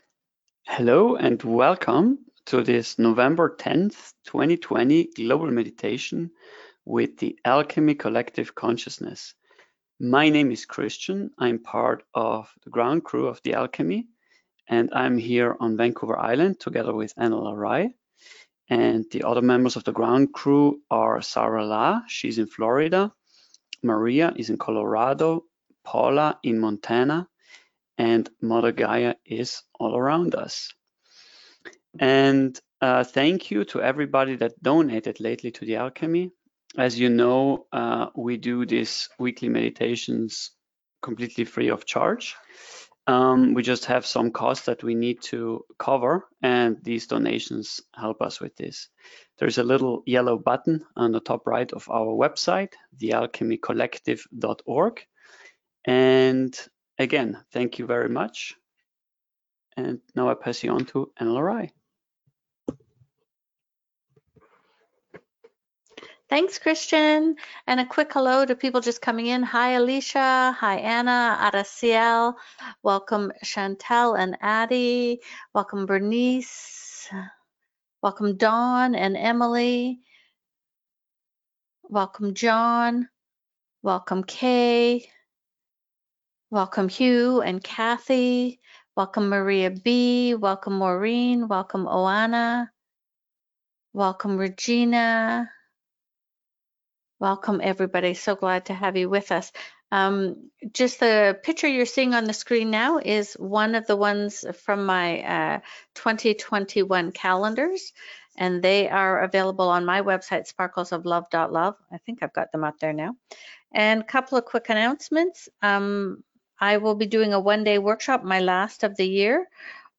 (online global meditation 2020-12-29)